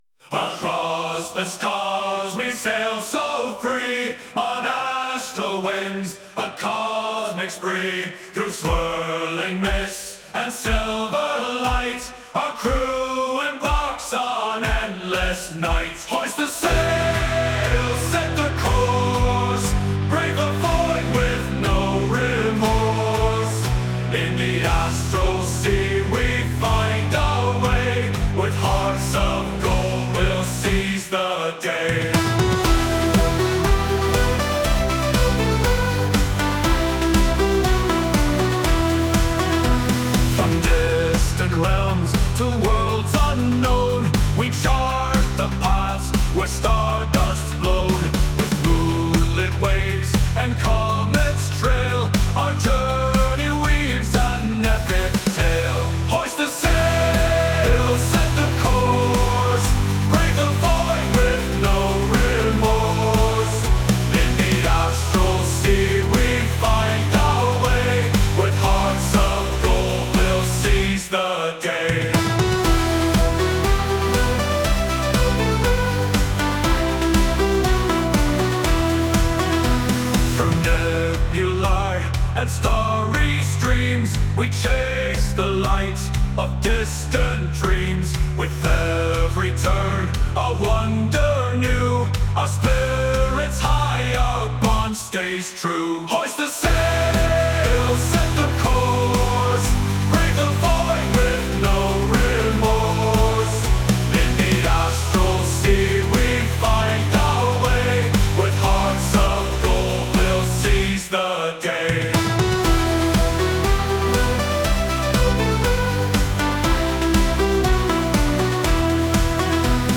Sailing the Astral Sea - Sea Shanty.mp3